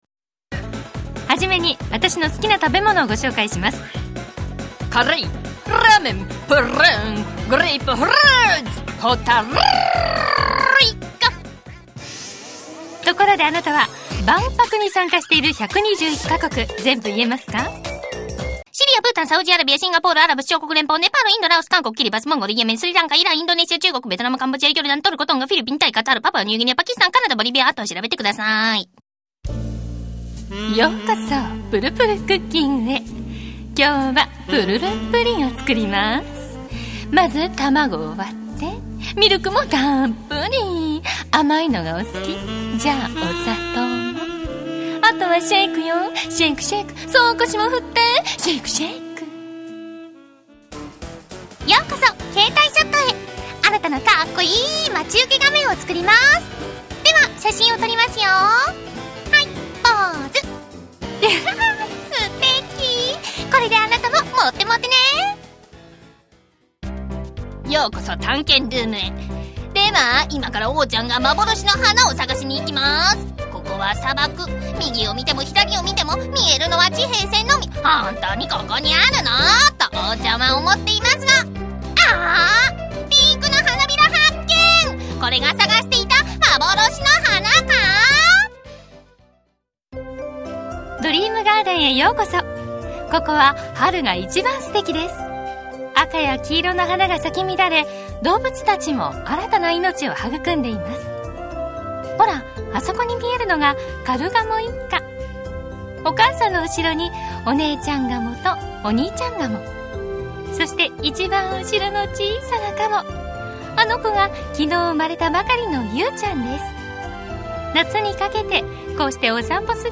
●ナレーション・歌 ボイスサンプルはこちら●
海外ドラマ風がお気に入りです。
海外ドラマ風、ドッグカフェ、絵画、メイド、手作りアイランド、ミッキー？